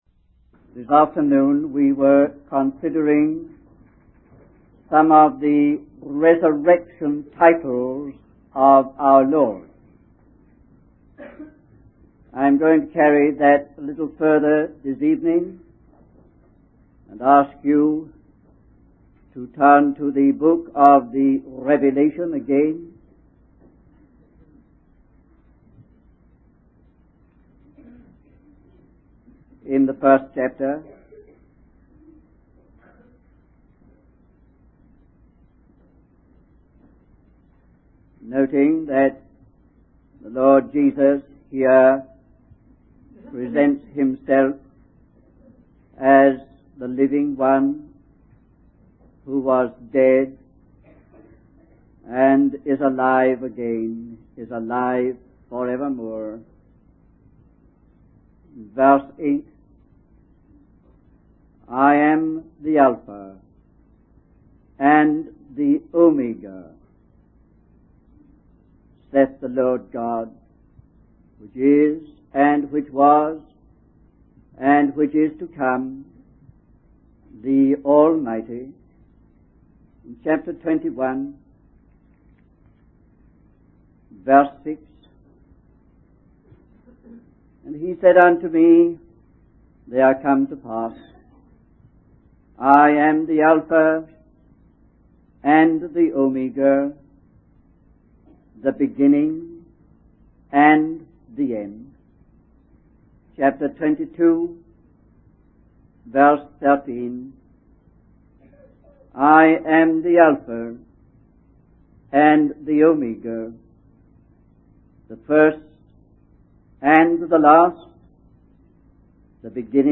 In this sermon, the speaker emphasizes that Jesus provides a pattern and design for life, giving it meaning and explanation. He states that many people struggle to make sense of life and find it confusing and chaotic.